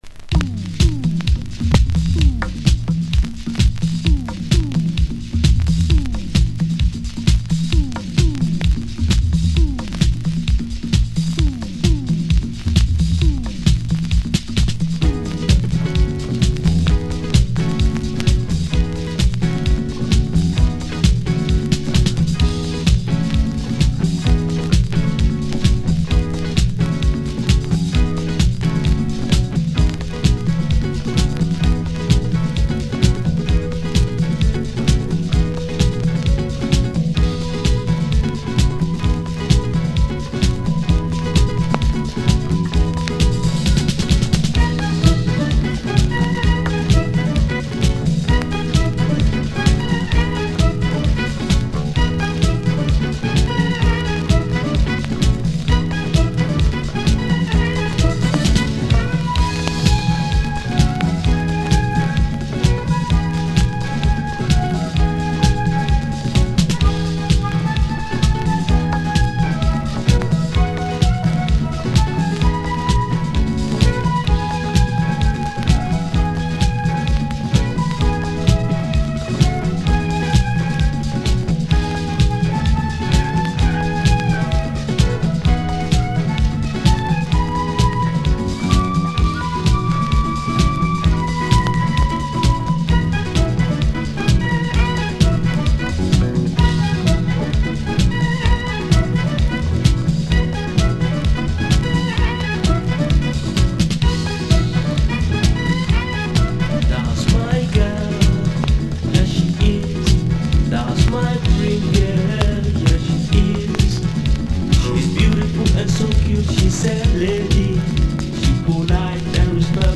Proto-house